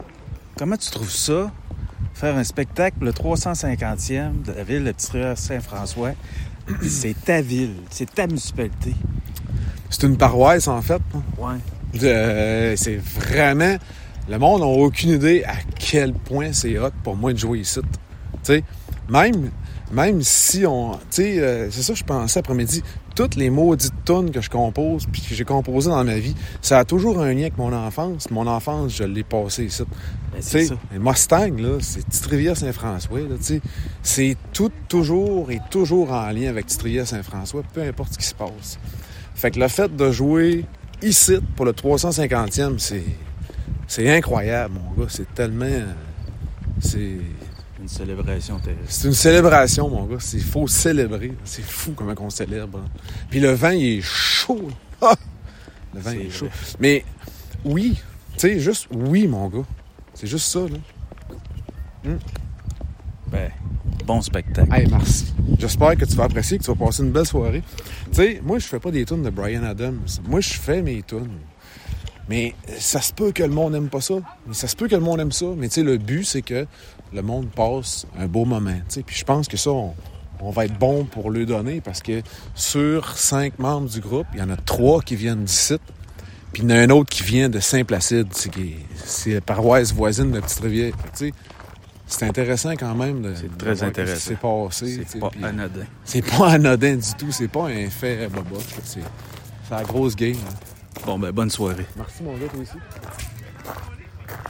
en entrevue.